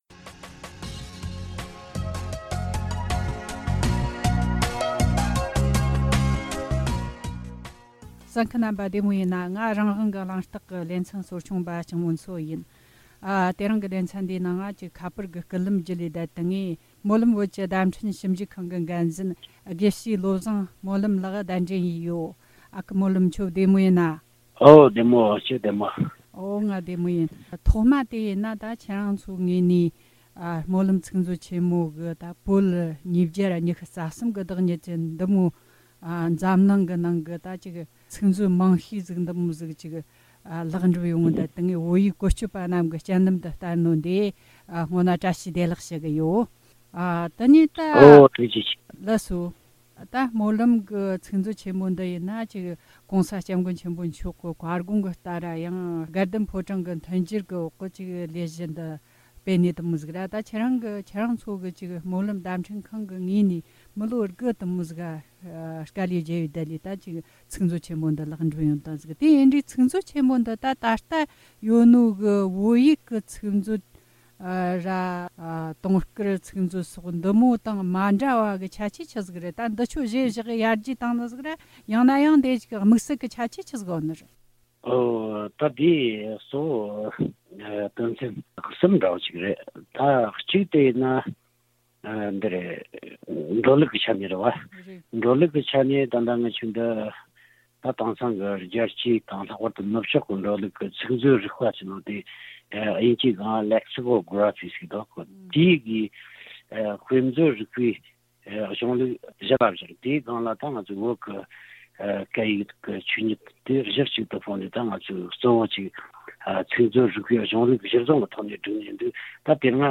གླེང་མོལ་བྱས་བར་གསན་རོགས་གནོངས།